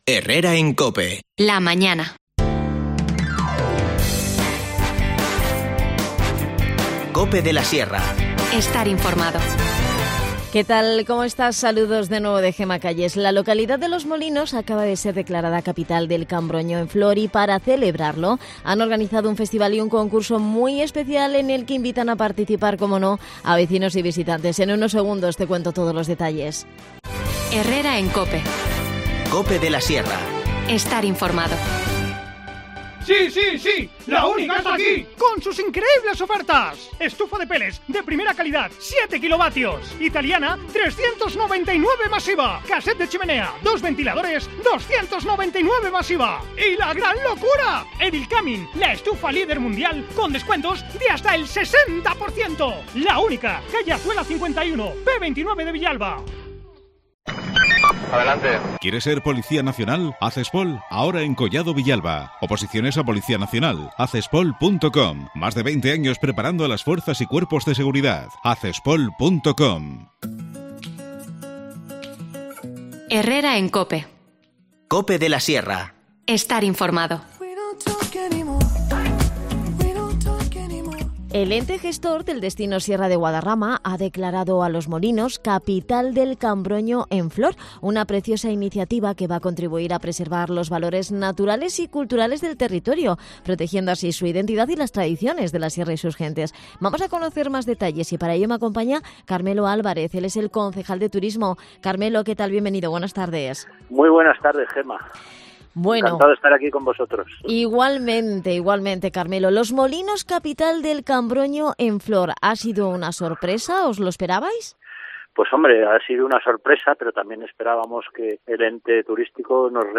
Carmelo Álvarez, concejal de Turismo, nos cuenta todos los detalles.